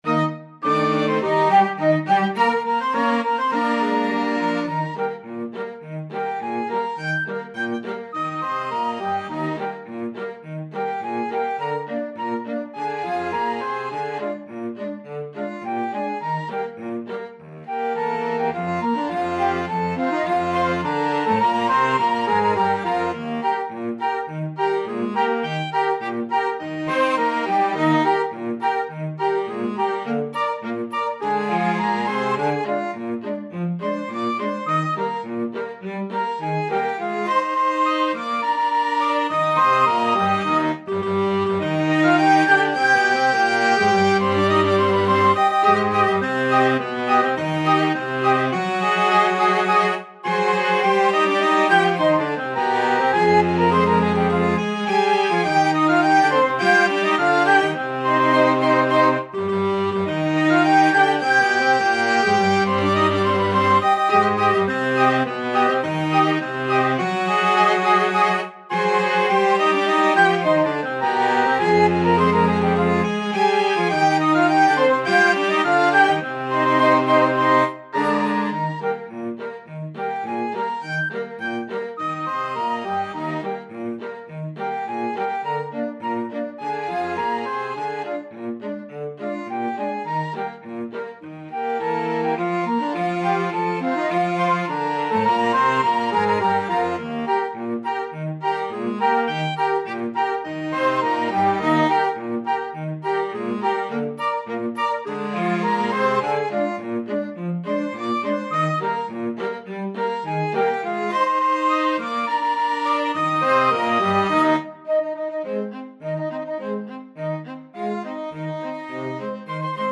FluteFlute (Chords)Clarinet
ClarinetPlayer 4 -- TromboneCelloTuba
Quick March = c.104
2/2 (View more 2/2 Music)